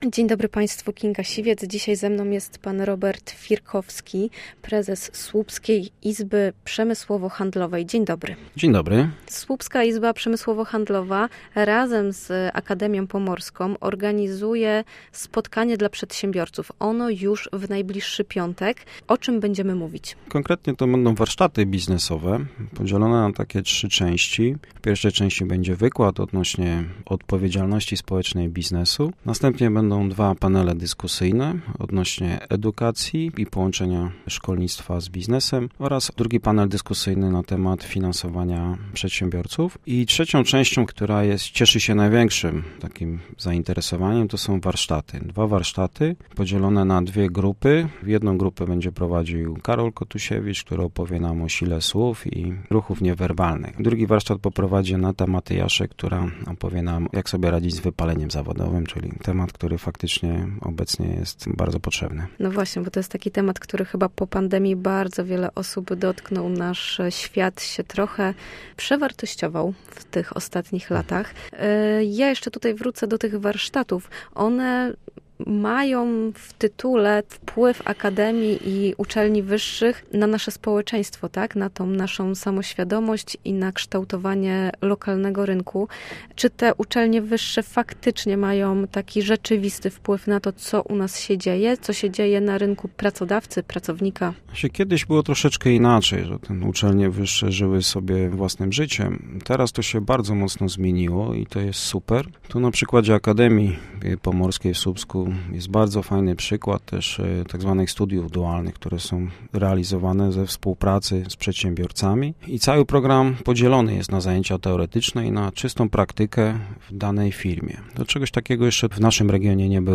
O kondycji lokalnego rynku pracy mówił na naszej antenie